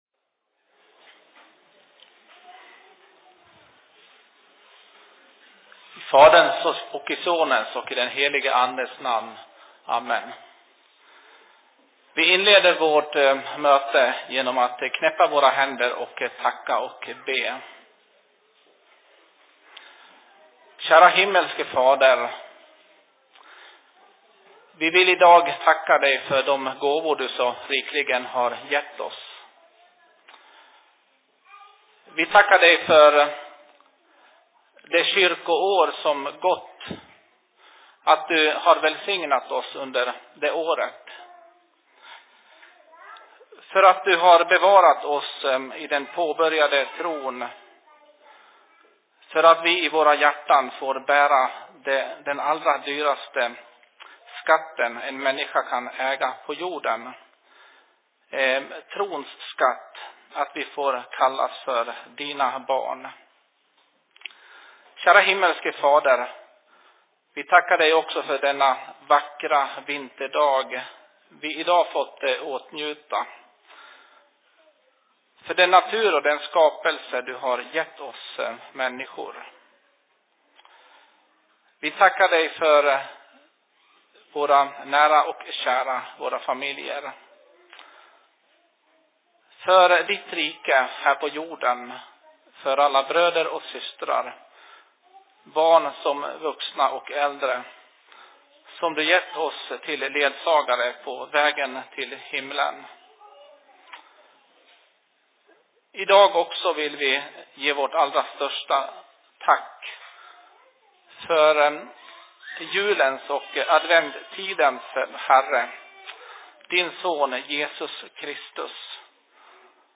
Mötet/Se Predikan I Dalarnas Fridsförening 01.12.2019 16.01